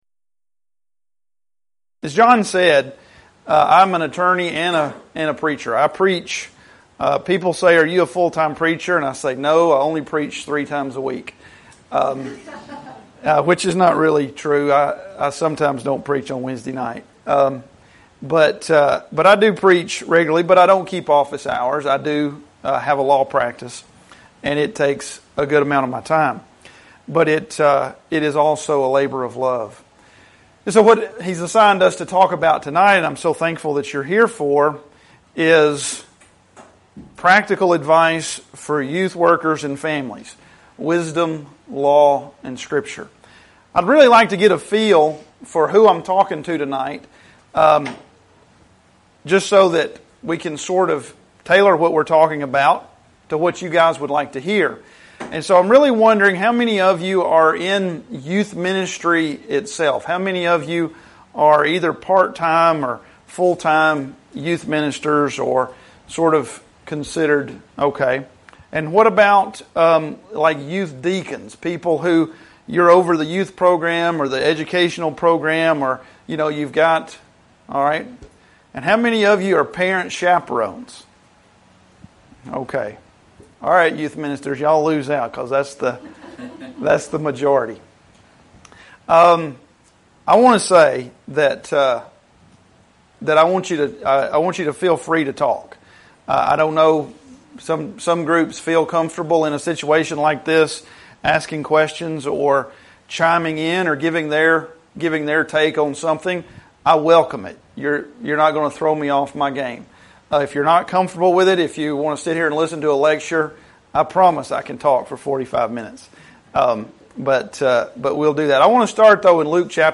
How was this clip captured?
Event: 2015 Discipleship University